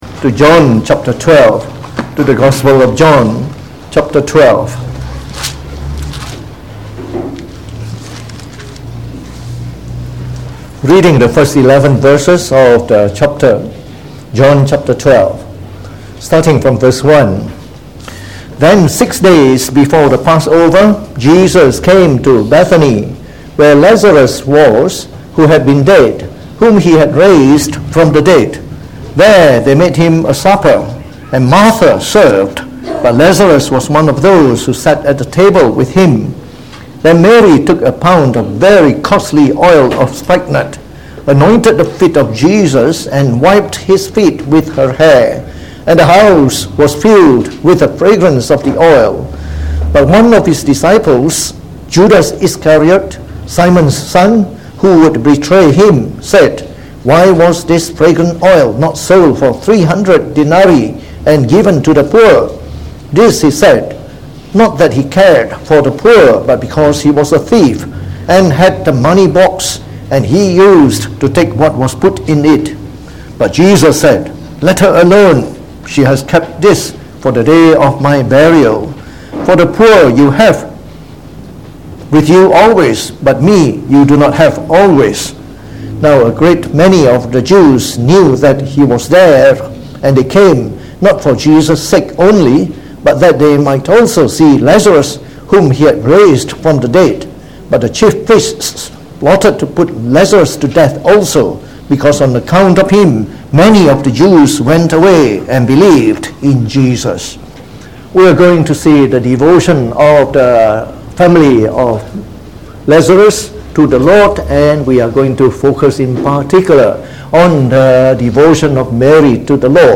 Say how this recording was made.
Preached on the 24th February 2019.